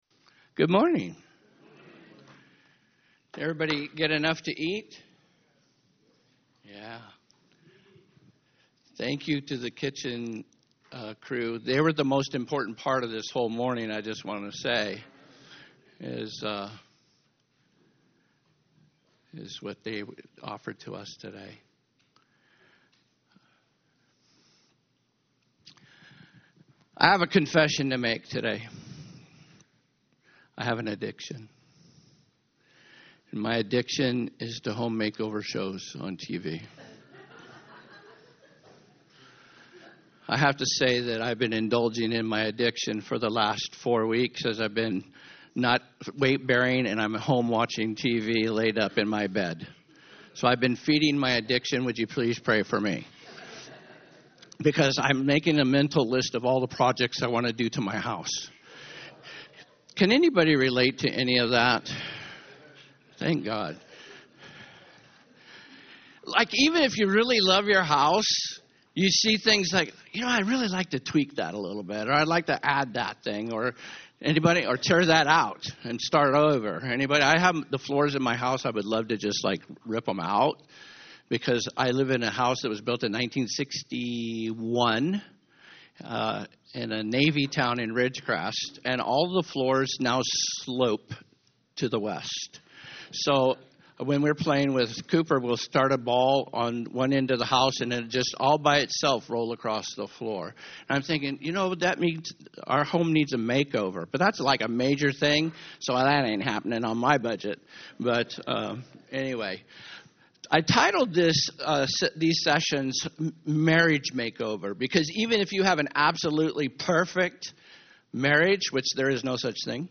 First session of the VCC Marriage Conference 2025